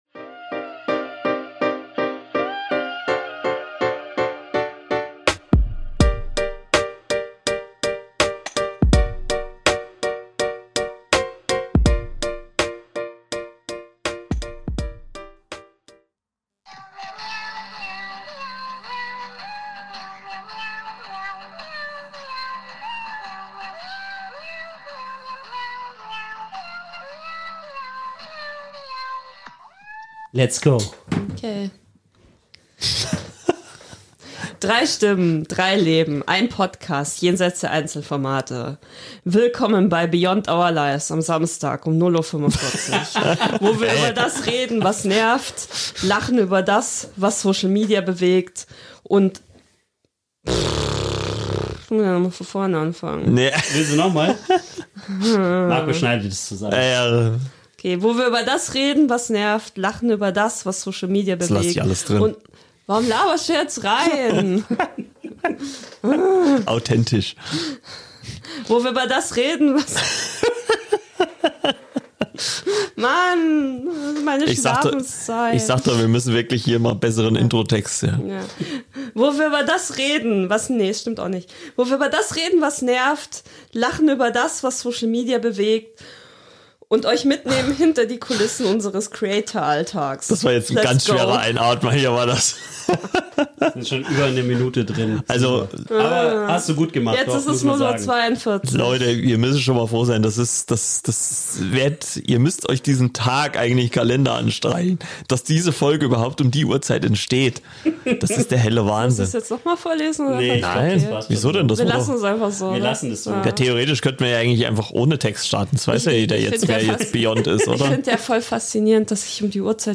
Drei Hosts im Gespräch